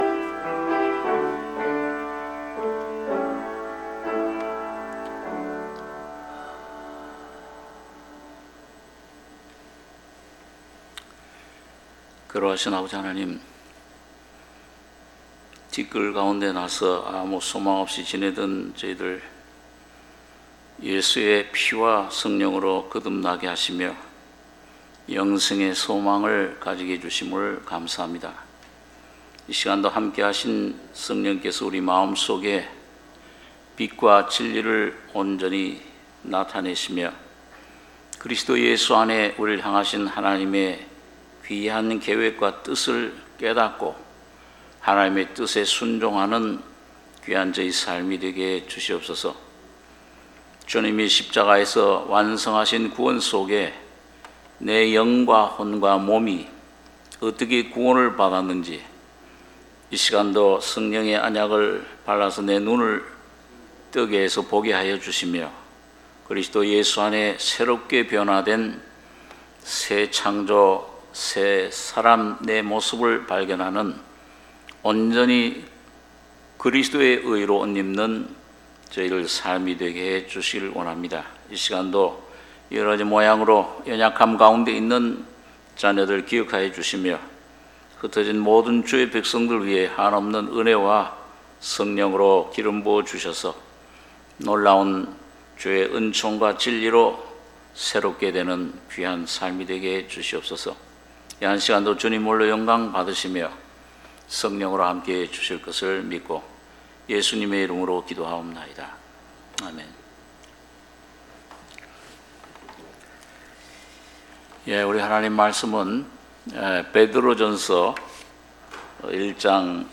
수요예배 베드로전서 1장 1절 ~ 9절